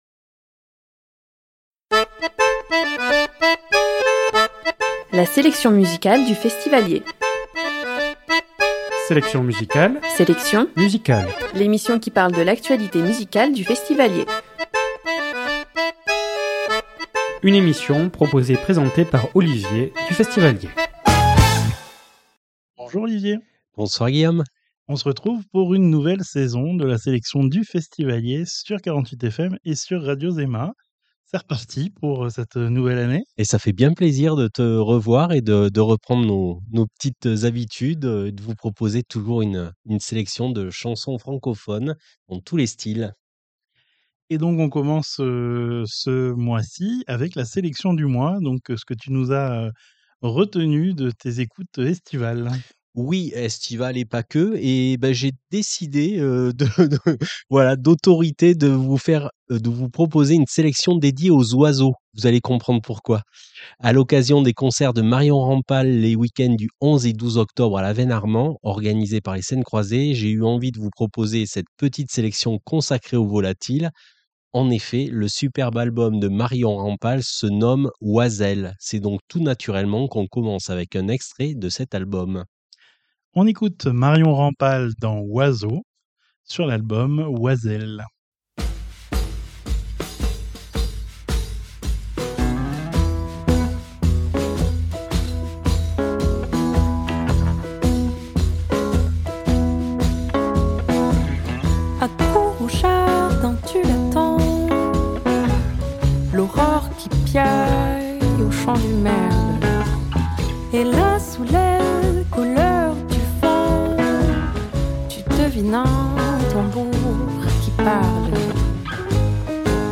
Emission du vendredi 10 octobre 2025 à 19hRediffusion le dimanche suivant à 21h